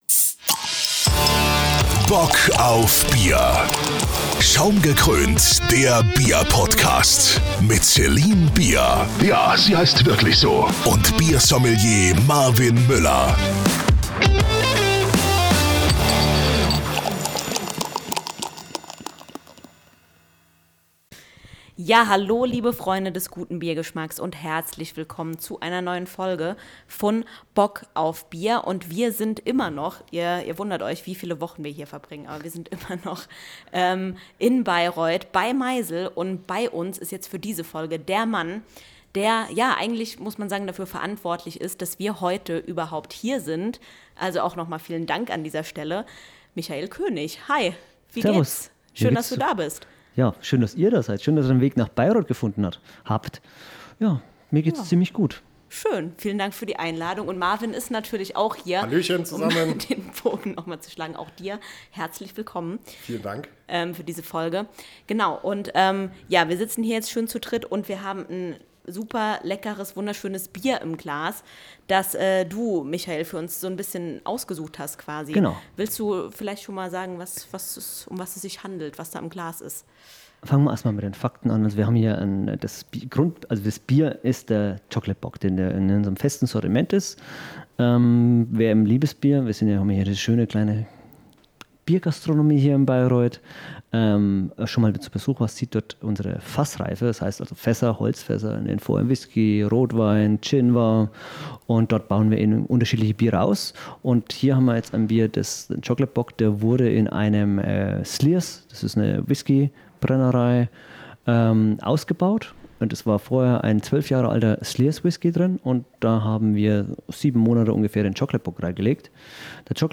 Zusammen mit euch geht es heute wieder ein paar Wochen zurück zu unserem Besuch beim Homebrew Event in Bayreuth